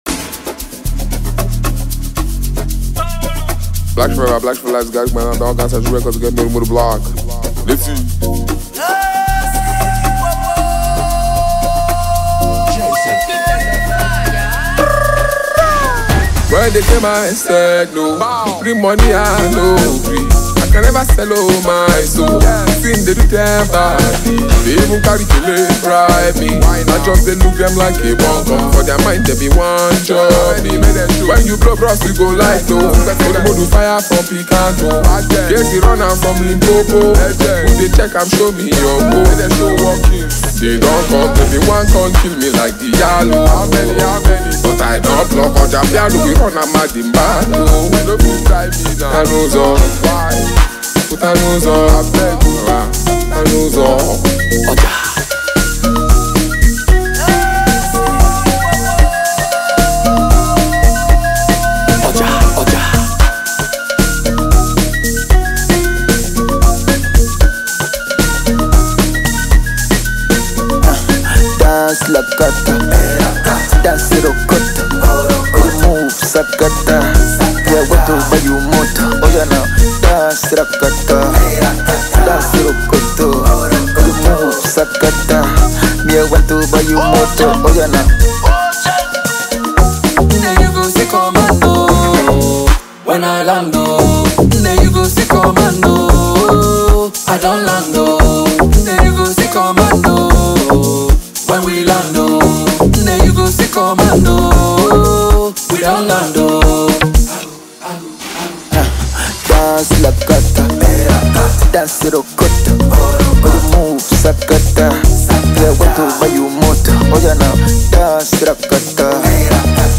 Scorching talented Nigerian singer